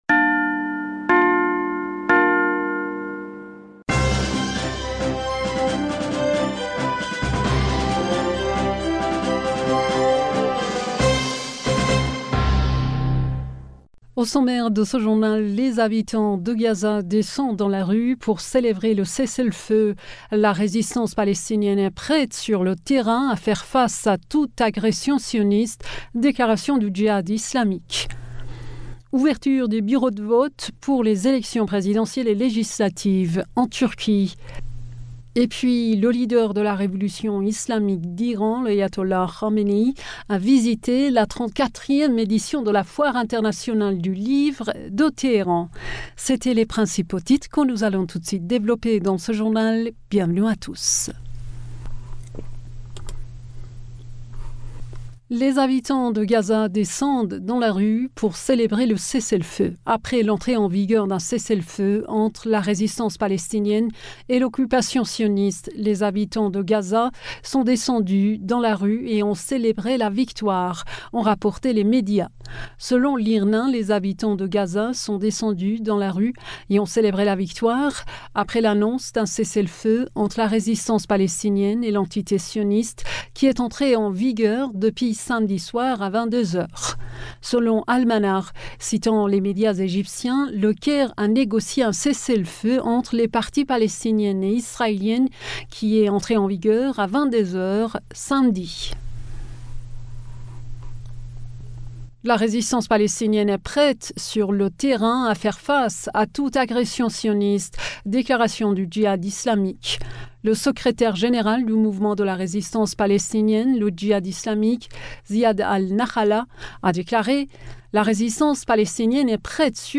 Bulletin d'information du 14 Mai 2023